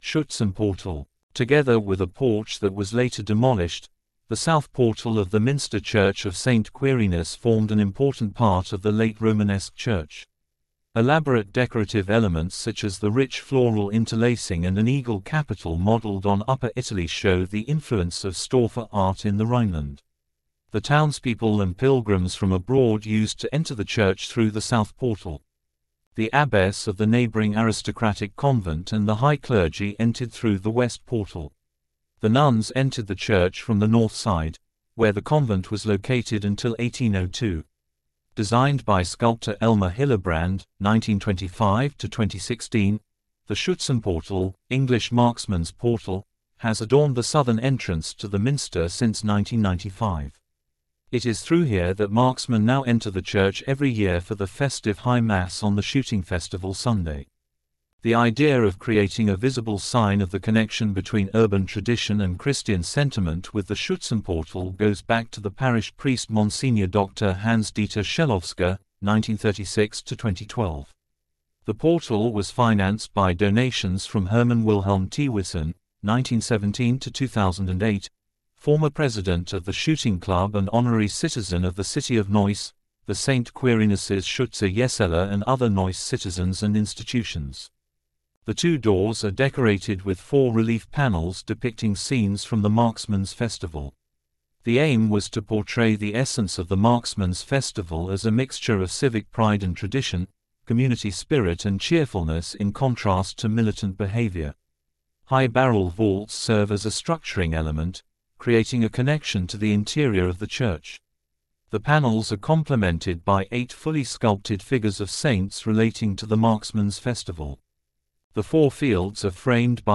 Audio Guide English